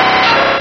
Cri d'Insécateur dans Pokémon Rubis et Saphir.